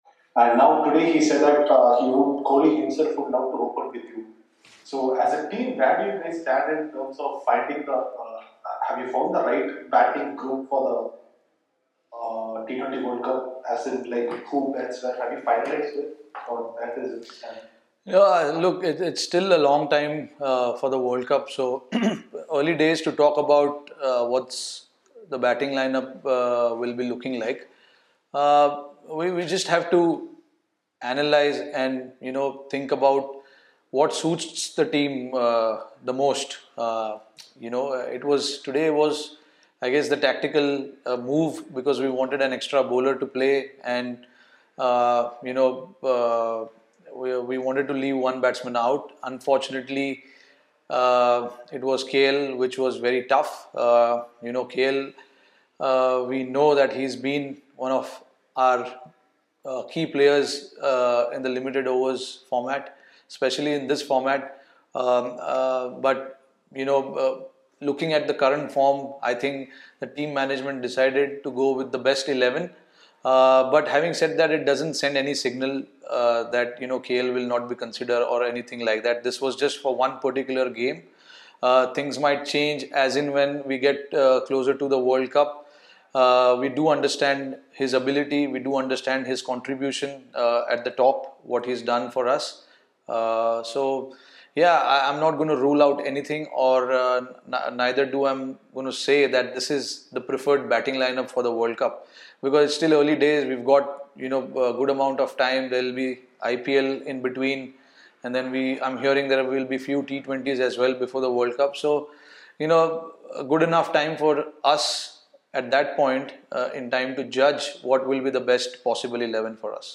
Rohit Sharma, vice-captain, Indian Cricket Team, addressed a virtual press conference after the 5th and final Paytm India-England T20I played at the Narendra Modi Stadium in Ahmedabad.